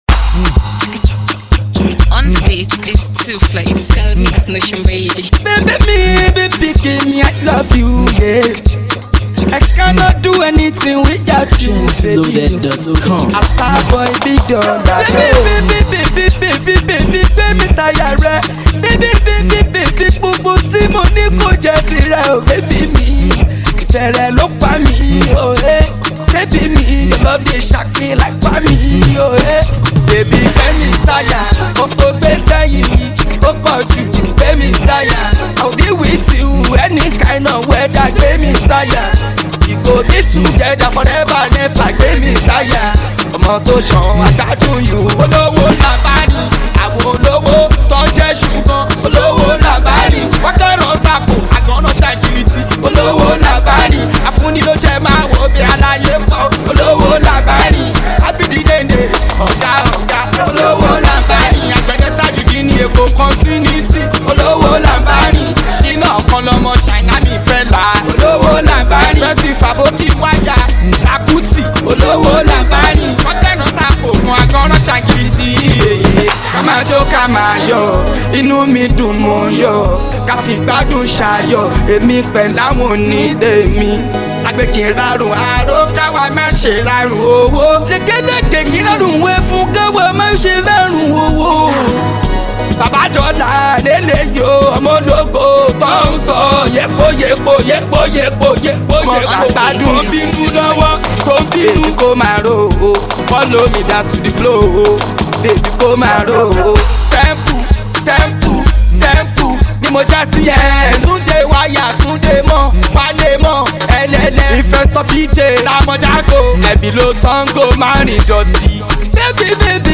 dance hall tune